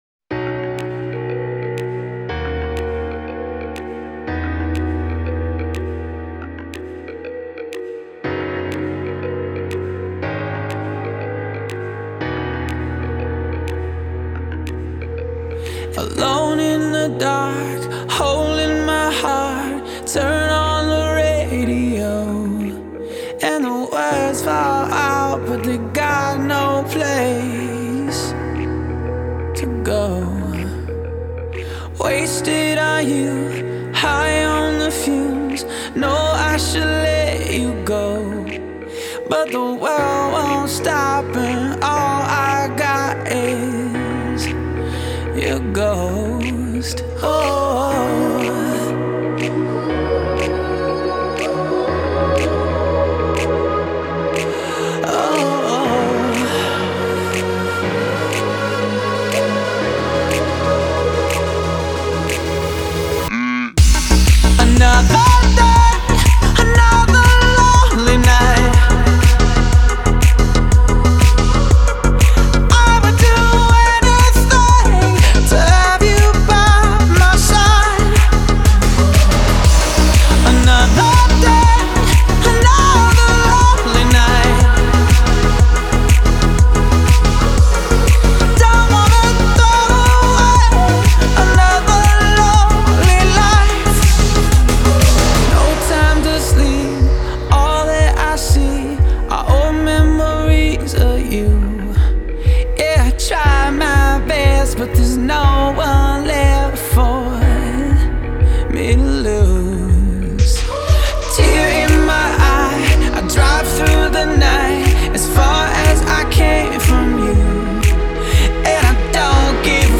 Genre: Pop, Dance